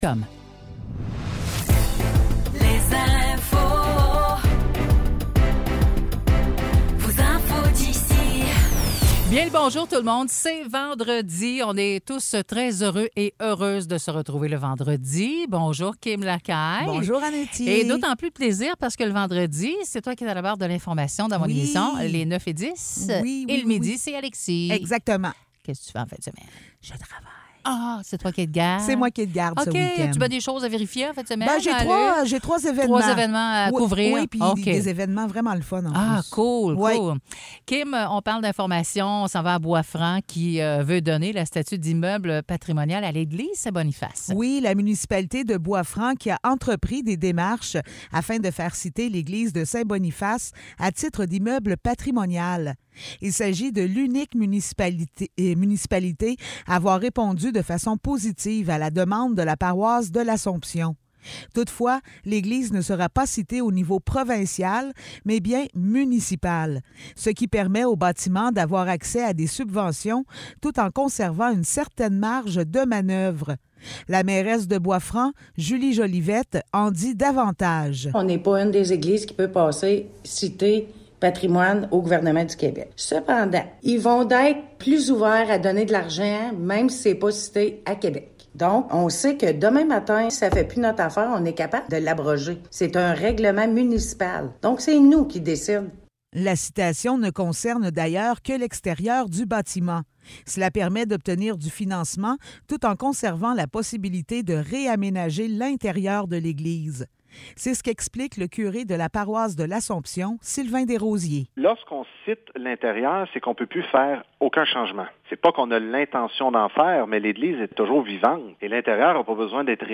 Nouvelles locales - 3 novembre 2023 - 9 h